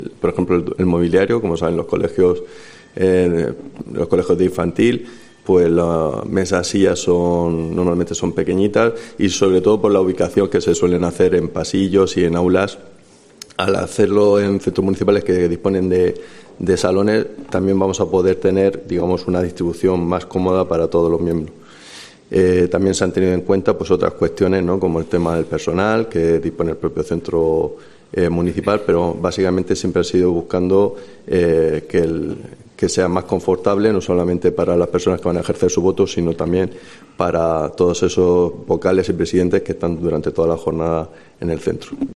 Marco Antonio Fernández, concejal de Pedanías y Vertebración Territorial